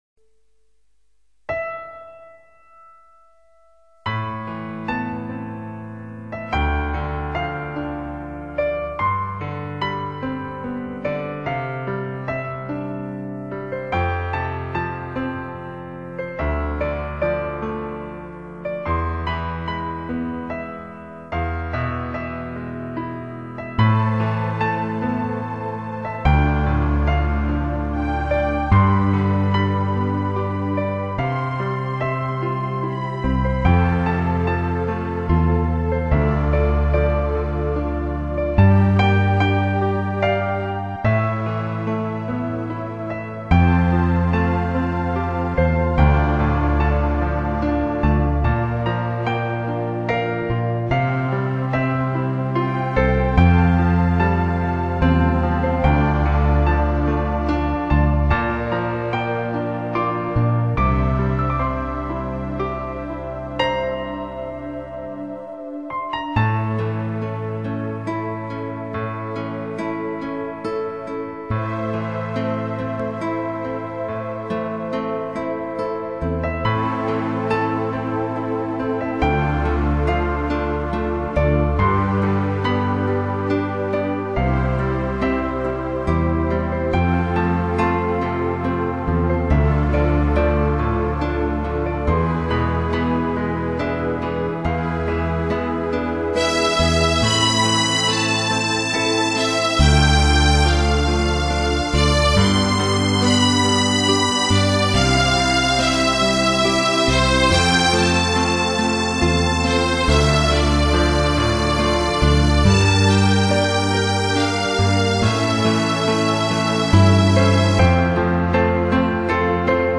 艺术家: 纯音乐
如诗如画般的旋律，每一个音符都是那么神秘，空灵，曼妙，极具召唤力；每一首